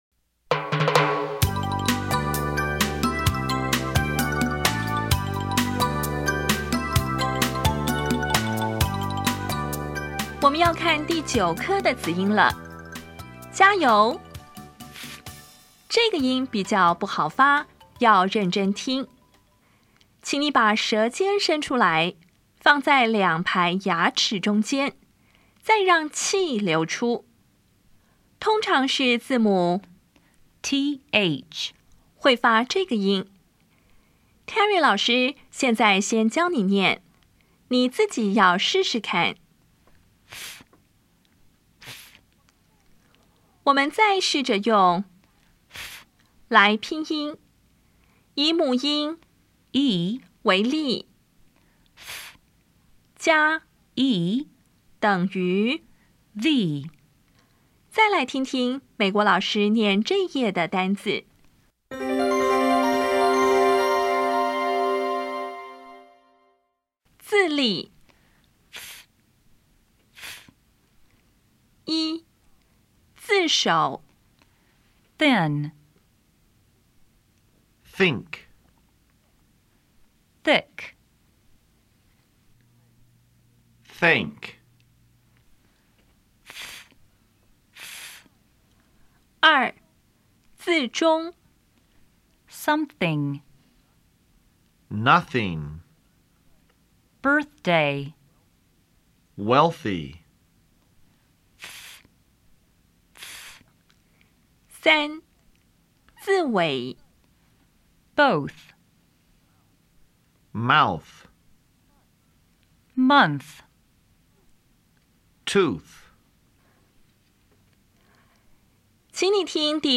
当前位置：Home 英语教材 KK 音标发音 子音部分-1: 无声子音 [θ]
音标讲解第九课
[θɪn]
比较[θ][t]      [θ](无声)  [t] (无声)